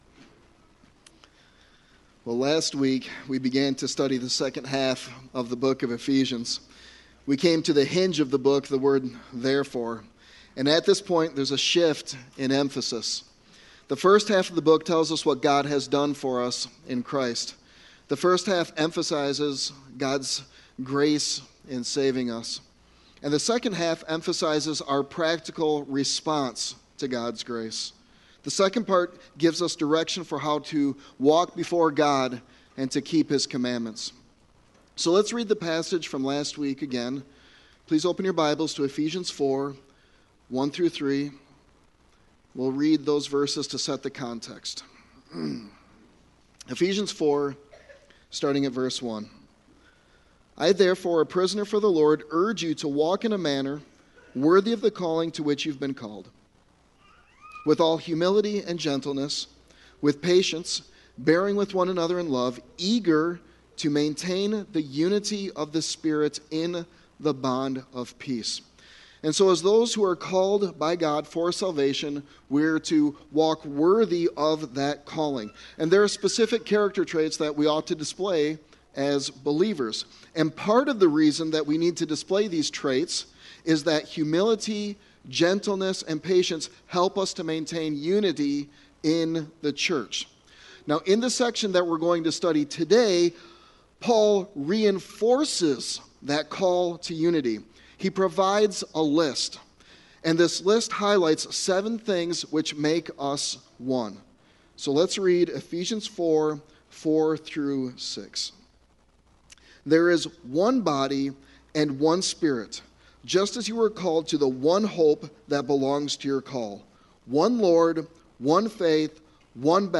Sermon Text: Ephesians 4:4-6